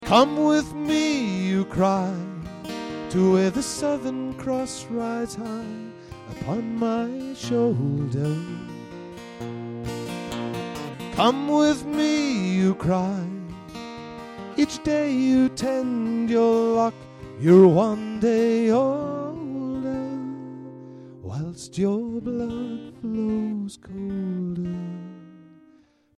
Ashington Folk Club - Spotlight 16 November 2006